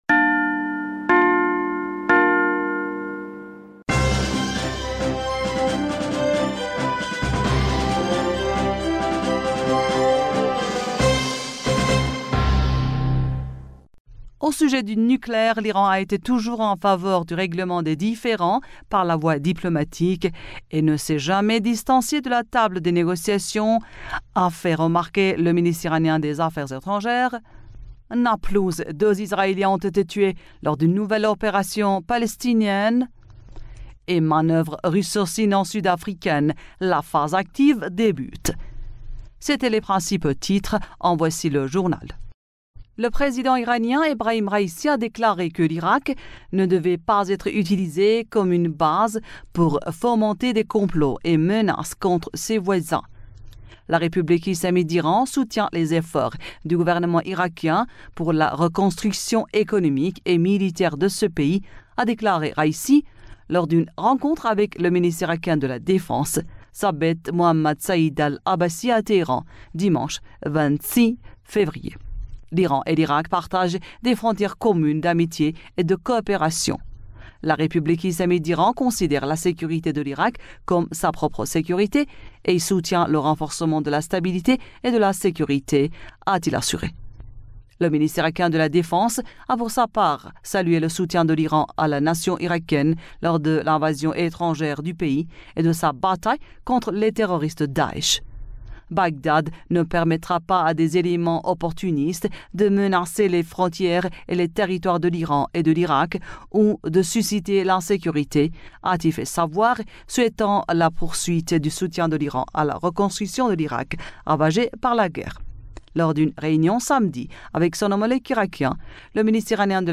Bulletin d'information du 27 Février